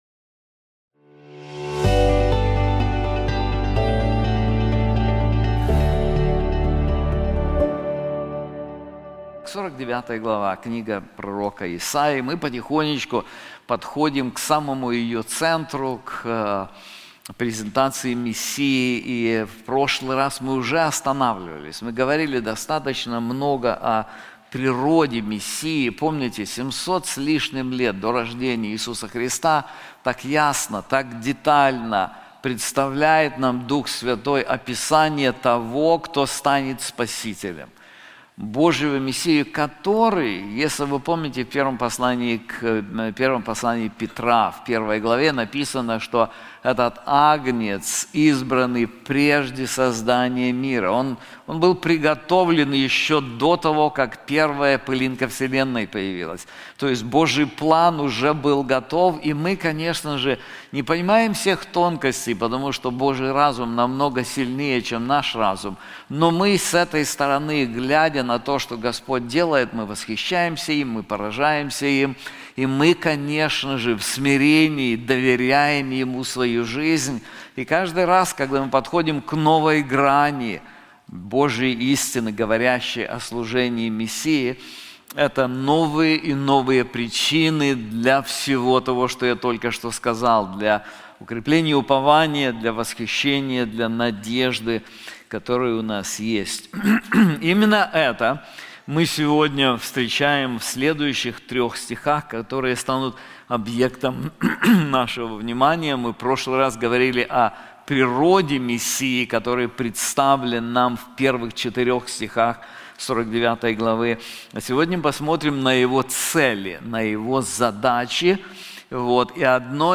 This sermon is also available in English:Will Israel Be Saved? / Global Purposes of the Messiah • Isaiah 49:5-8